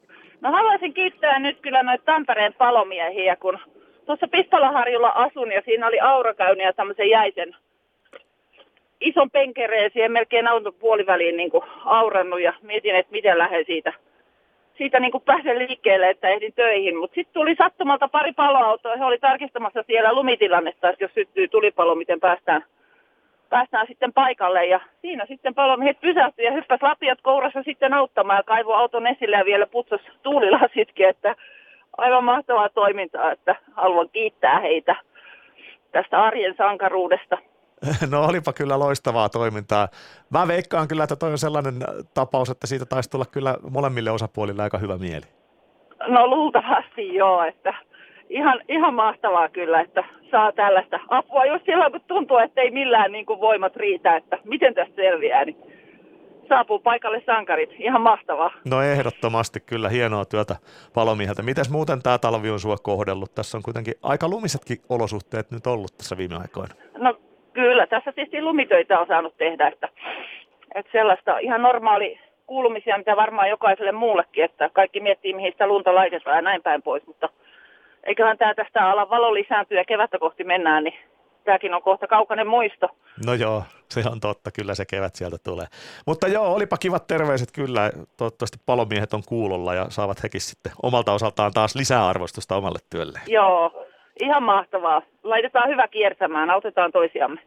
Päivän haastattelu